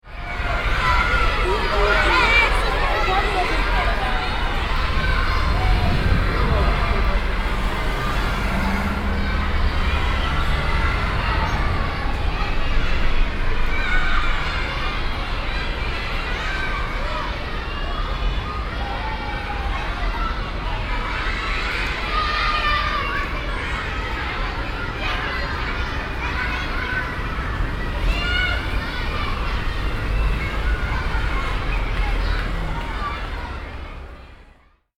Rumore
Rumore Intervallo È l'ora dell'intervallo e gli scolari giocano in cortile Ivrea, Via Dora Baltea
Microfoni binaurali stereo SOUNDMAN OKM II-K / Registratore ZOOM H4n
Intervallo.mp3